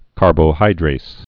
(kärbō-hīdrās, -drāz)